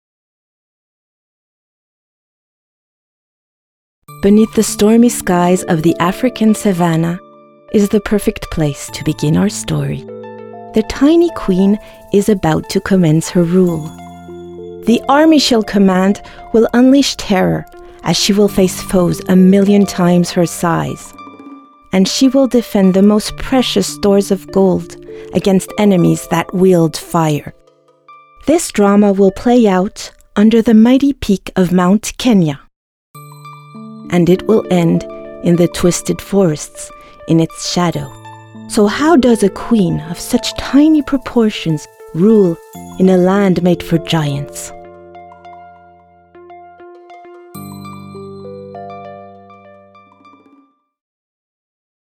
a Canadian and French bilingual vo actress with a natural, clear medium voice
Sprechprobe: Industrie (Muttersprache):